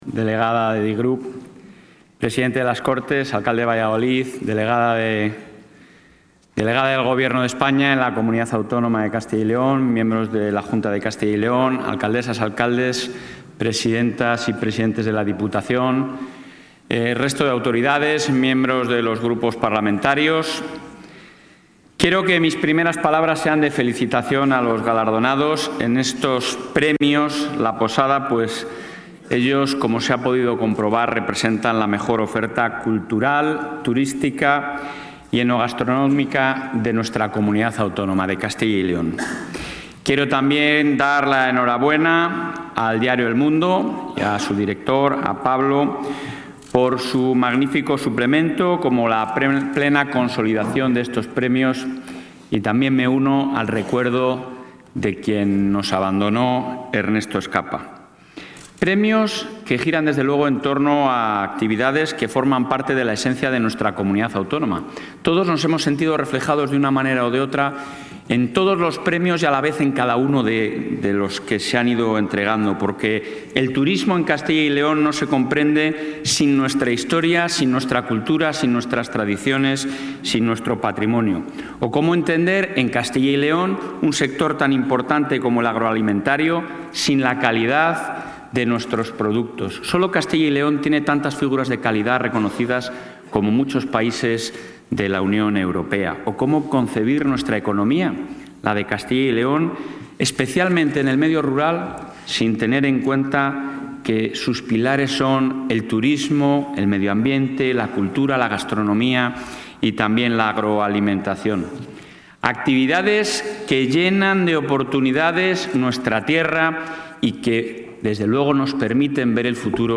Durante la entrega de los séptimos Premios “La Posada”, de El Mundo de Castilla y León”, el presidente del Ejecutivo...
Intervención del presidente.